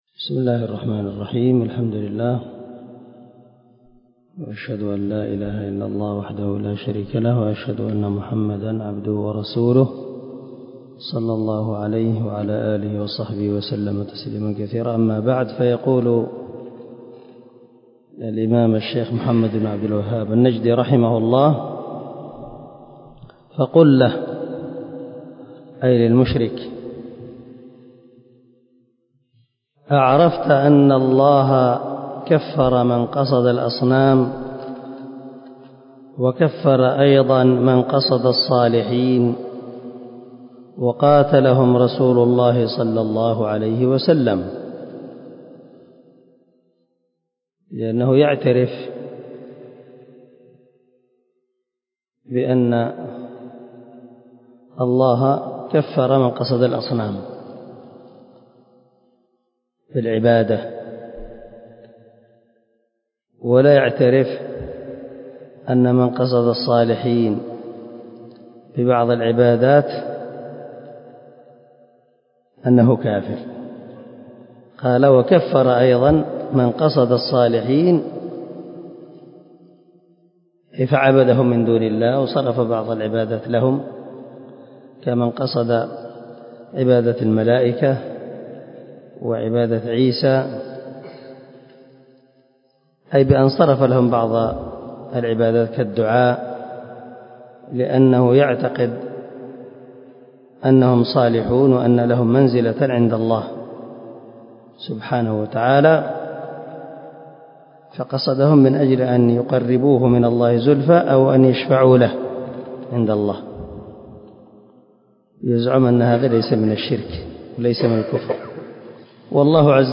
0008 الدرس 7 من شرح كتاب كشف الشبهات